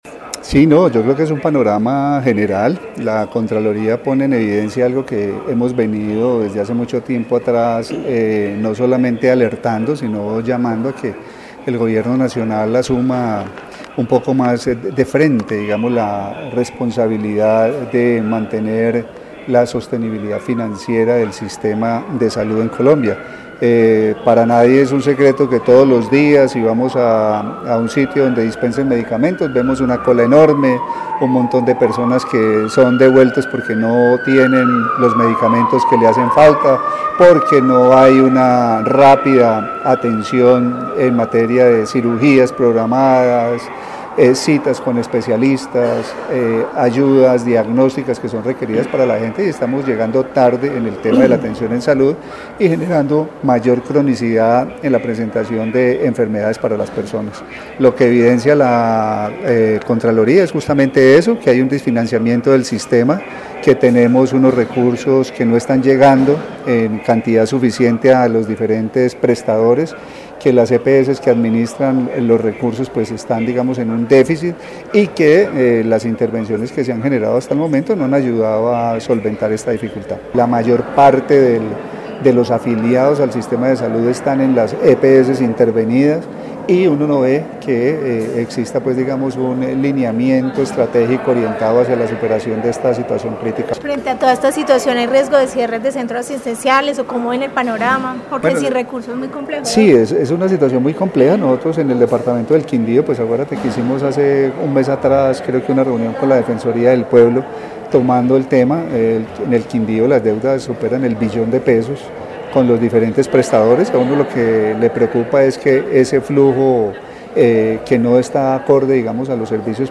Secretario de Salud del Quindío